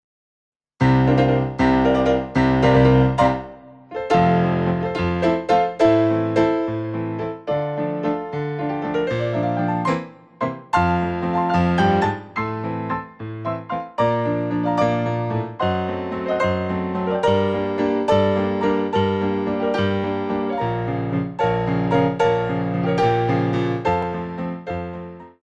Show Tunes for Ballet Class
Grands Battements en Clôche
6/8 (8x8)